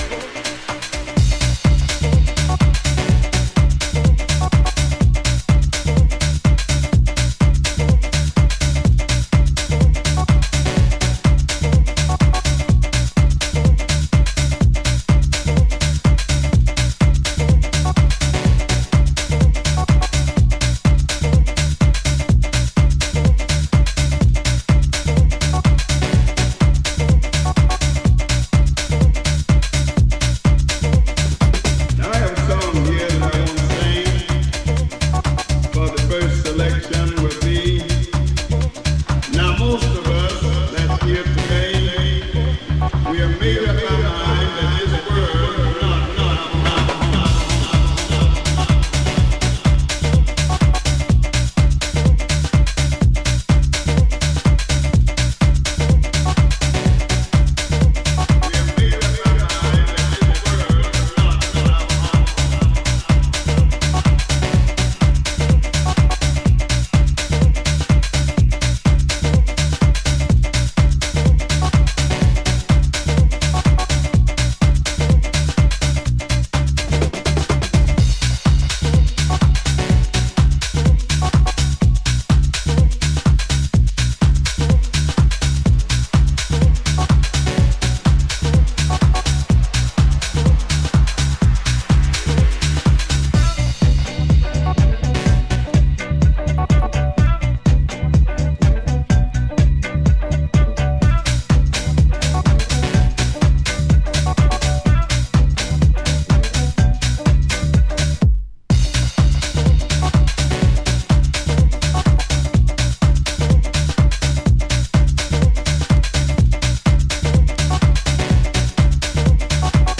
ミニマルなファンク感を活かした程よいテンションのハウス・トラック